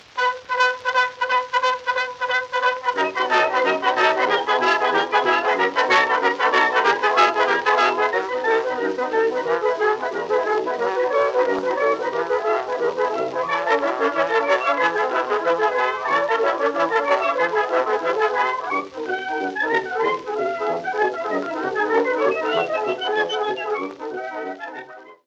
Hier drei Hörbeispiele zum akustischen Verdeutlichen der genannten Zahlenverhältnisse:
Hungarian Rhapsody, 1905. Gusswalze im Standardformat, Ausschnitt.